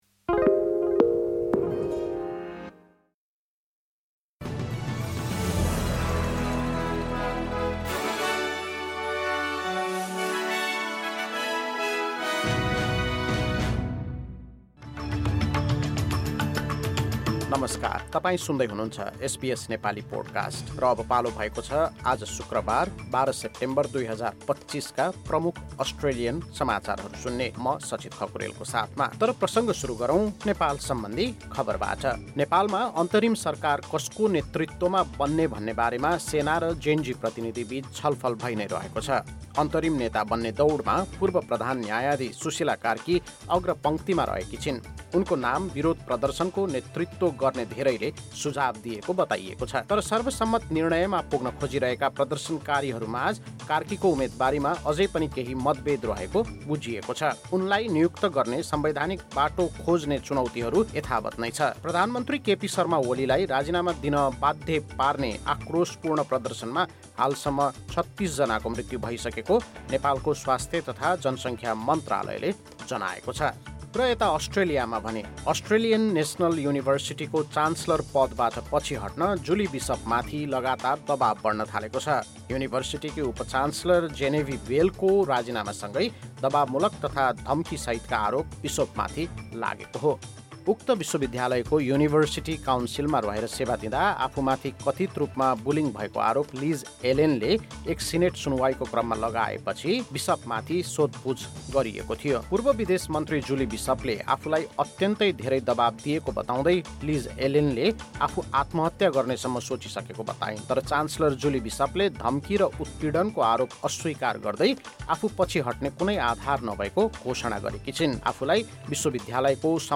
आजका प्रमुख अस्ट्रेलियन समाचार छोटकरीमा सुन्नुहोस्।